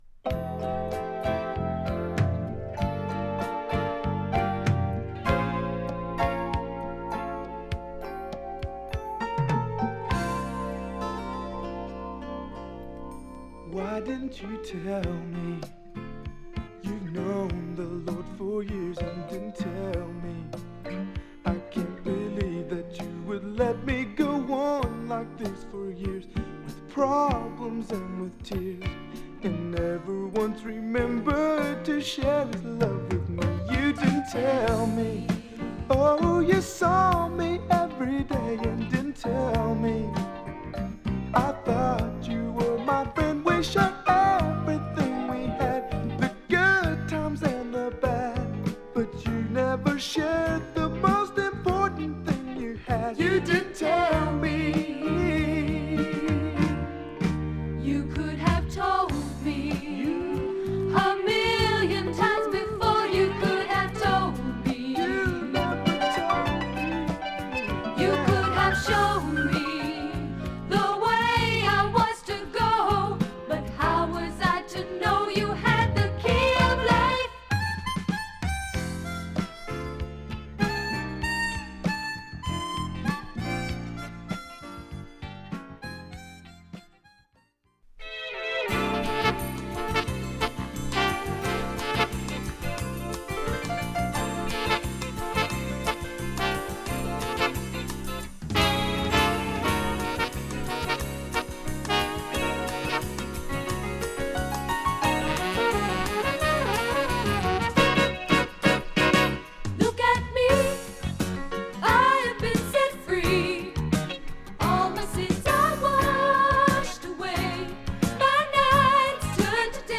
英レリジャス系アーティストによる1981年リリース作品。